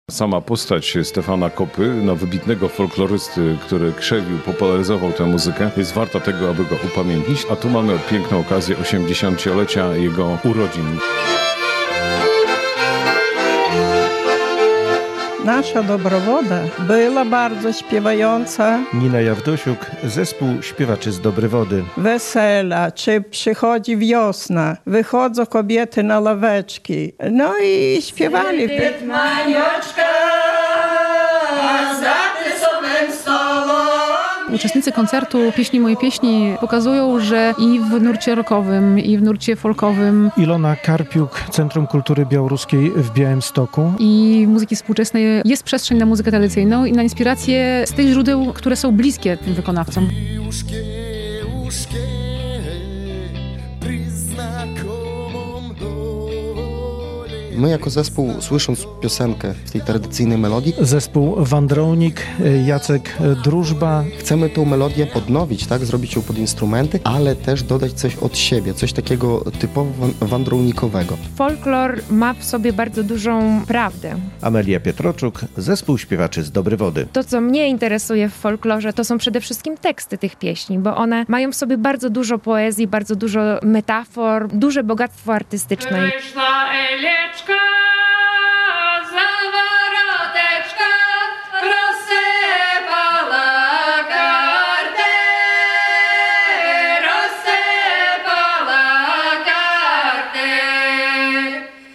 "Pieśni, moje pieśni…" to nazwa czwartkowego (2.10) koncertu w Studiu Rembrandt Radia Białystok.